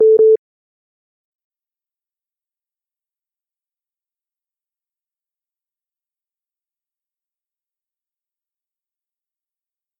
incoming_second_call.mp3